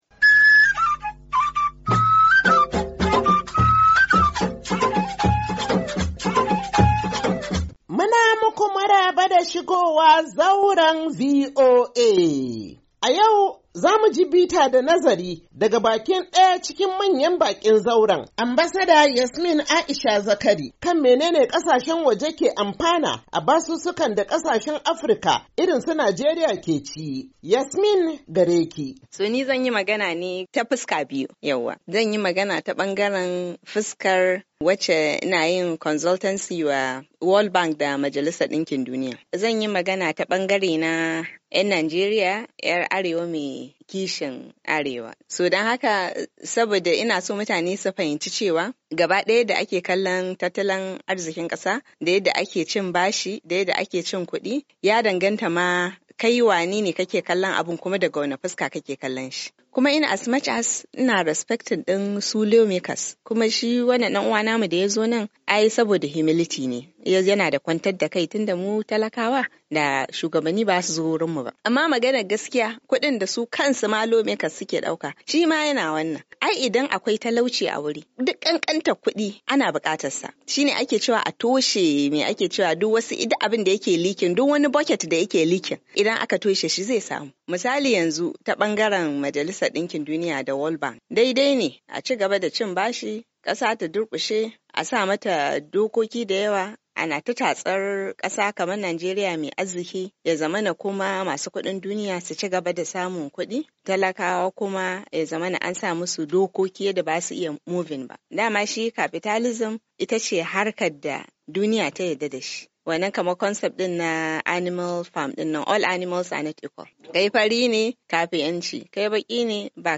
Shirin Zauran VOA na wannan makon, zai kawo muku ci gaba da tattaunawa kan yadda Najeriya ke karbo bashi, inda Ambasada Yasmin Aisha Zakari ta yi fashin baki kan abin da cin bashi ya ke nufi ga manyan Hukumomin Majalisar Dinkin Duniya da kuma Bankin Duniya, idan ya shafi kasashen Afirka.